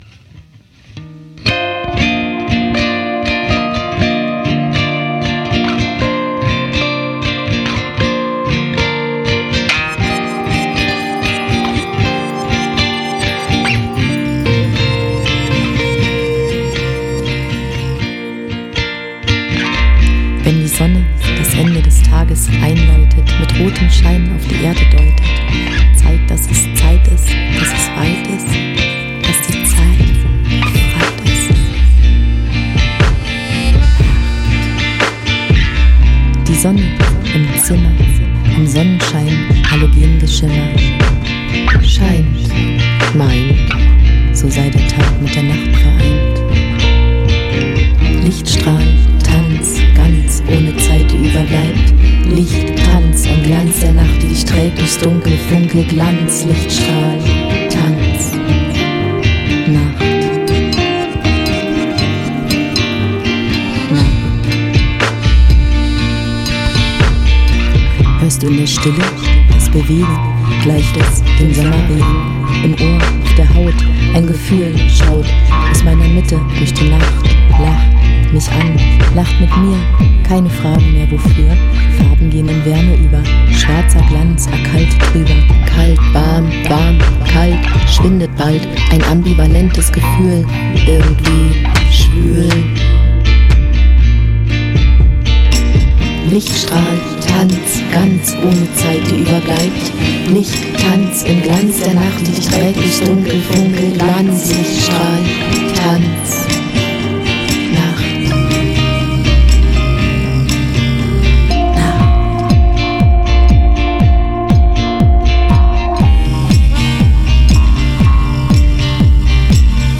Hallo liebe Forumsgemeinde, bei diesem Schatzi handelt es sich um ein Lied, das von der Nacht berichtet. Eigentlich war der Hit mal eine reine Aksutikversion. Nun war mir schon lange danach eine Elektroversion davon zu machen.
Kompressor habe ich überhaupt erstmal raufgemacht, Lautstärke habe ich allgemein bissl angehoben (manchmal gut, manchmal schlecht).
Jetzt werden die Gitarren angepasst/weggedrückt.
aber sehr effektvolle Basseinlage und die Staccato (sagt man das so?) Streicher.